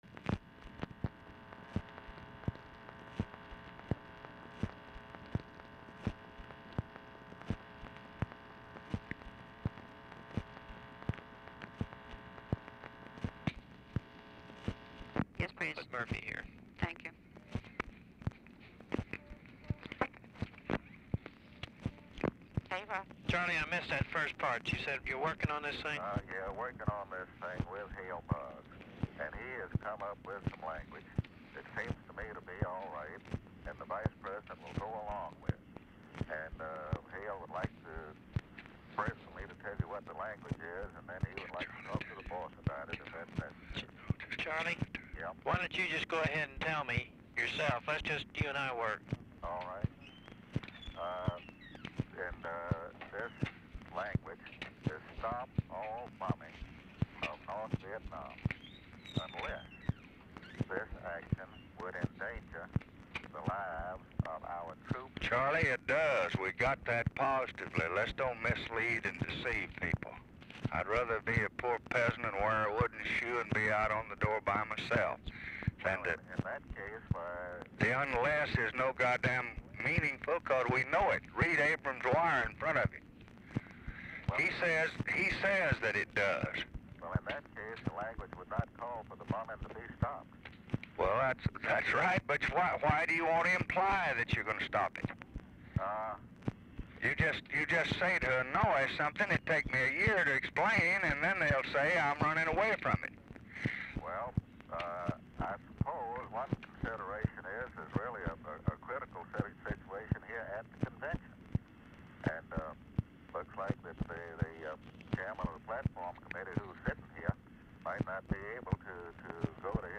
Telephone conversation # 13313, sound recording, LBJ and CHARLES MURPHY, 8/26/1968, 8:04AM | Discover LBJ
Format Dictation belt
Location Of Speaker 1 LBJ Ranch, near Stonewall, Texas